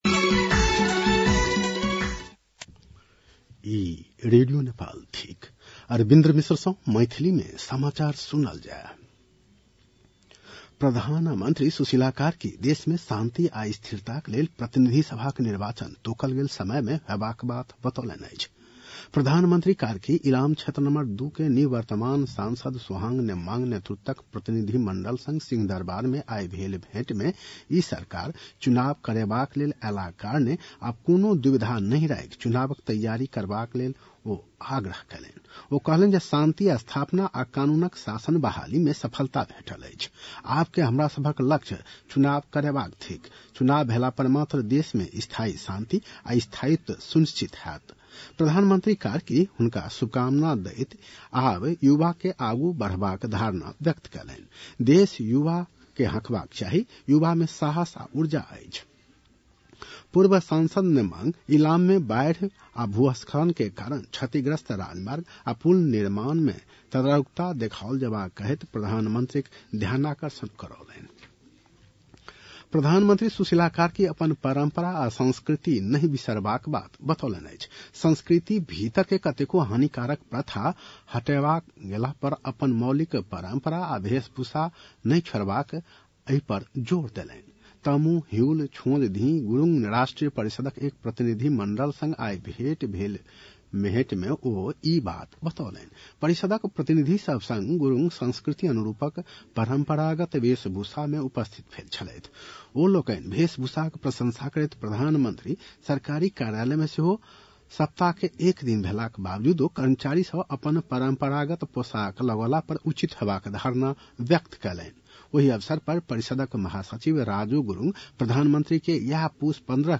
मैथिली भाषामा समाचार : ६ पुष , २०८२
Maithali-news-9-06.mp3